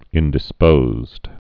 (ĭndĭ-spōzd)